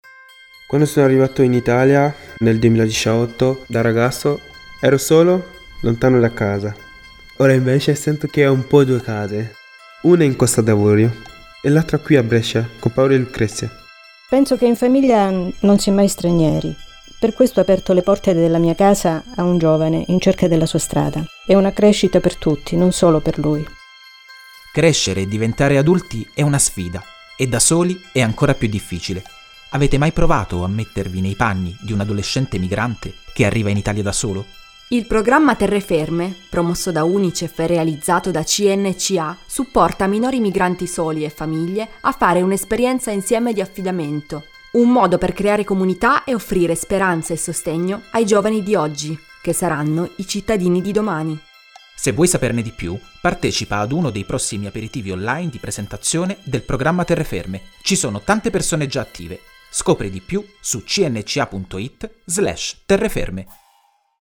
Spot-Terreferme.mp3